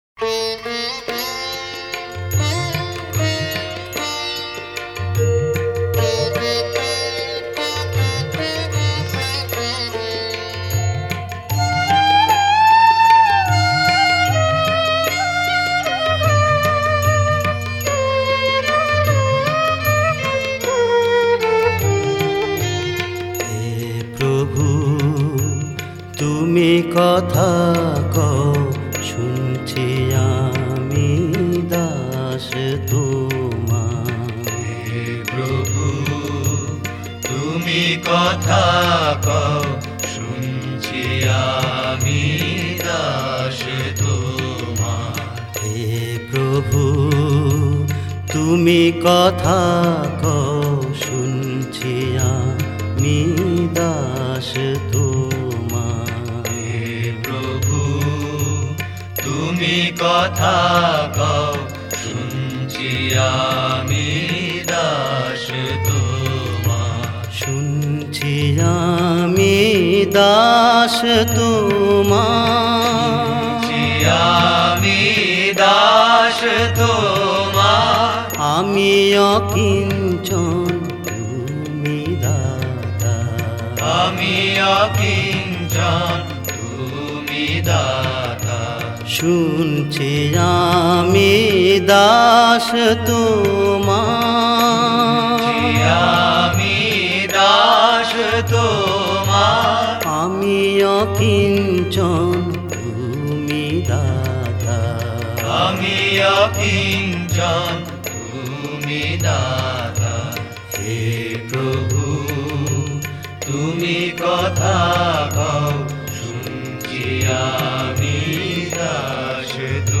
Directory Listing of mp3files/Bengali/Devotional Hymns/Bhajons/ (Bengali Archive)